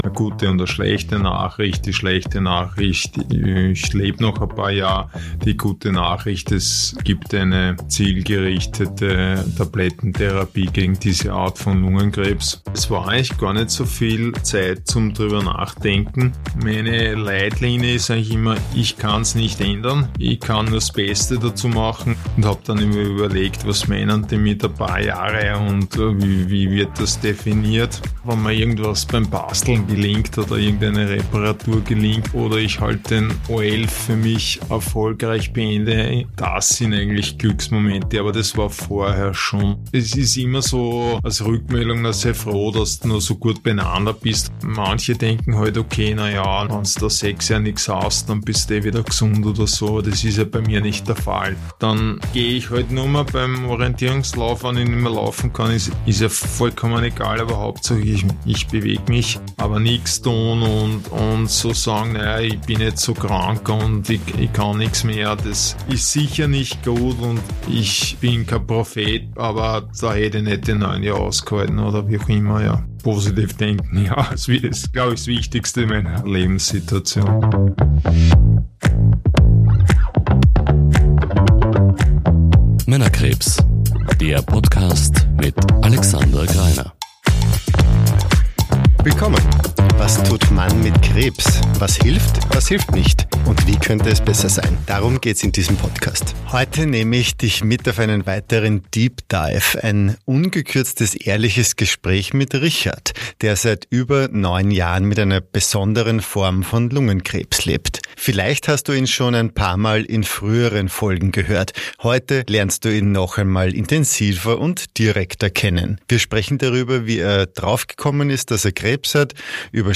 Gespräch) · Folge 15 ~ Männerkrebs – Was tut Mann mit Krebs?